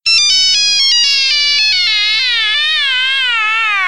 Categoría Nokia